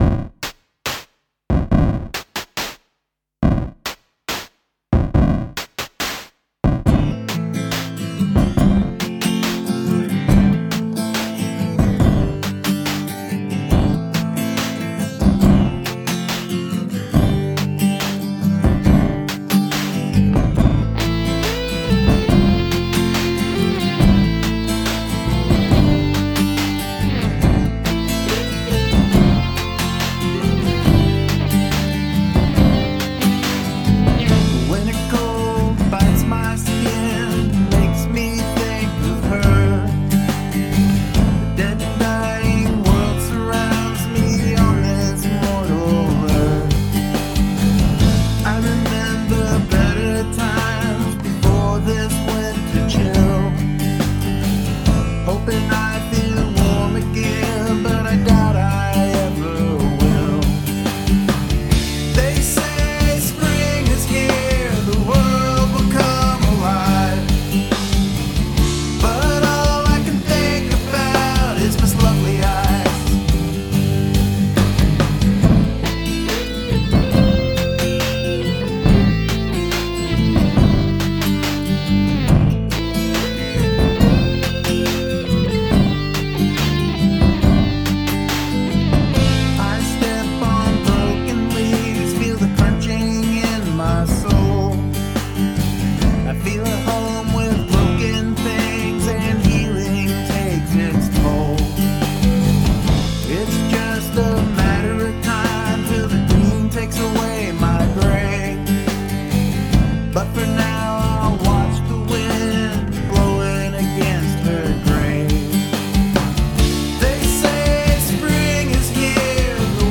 A combination of fake and real drums (real drums are samples, but they sound real. The fake drums are 8-bit crushed.)
I used a flanger on the acoustic guitar (and vocal a little bit, too) to give a bit of an unsettling feeling.
Well crafted tune, the beat drives it along nicely.
Love that 8-bit sound on the verse and the contrast with the soaring chorus (it's a killer!). The swirl of the flanger is very effective.
I love the edge of melancholy on the chords and the vocal that are perfect for the (excellent) lyric.
to this song... cool vocal panning you have here (or is that what the flange does?)
acoustic sounds nice... drums work fine too..